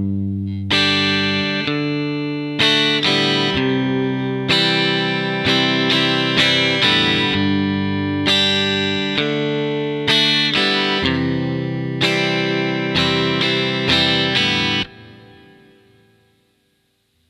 A kind of unwritten rule with rhythm guitar is that you can strum open strings just before a chord change (usually with an up-strum).